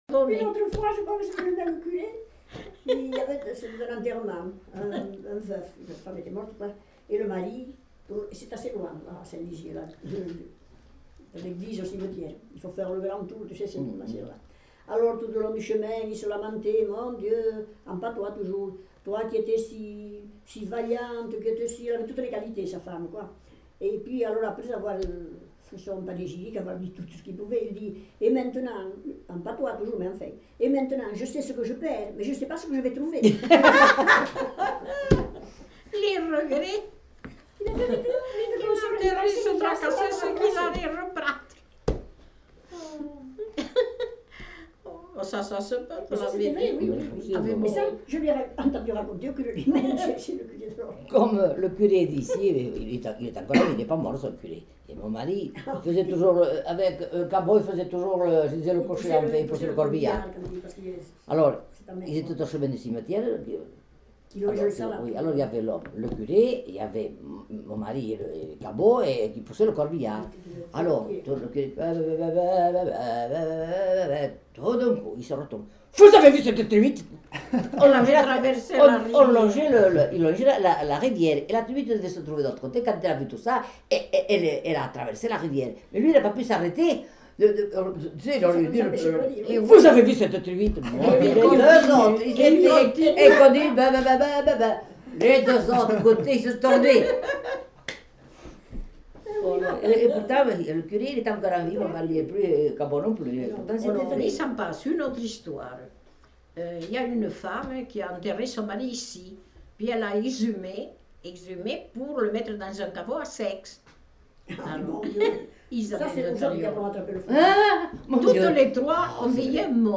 Aire culturelle : Couserans
Lieu : Couflens
Genre : conte-légende-récit
Effectif : 1
Type de voix : voix de femme
Production du son : parlé